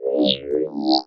Ps the sample I uploaded come kind of close but again... not enough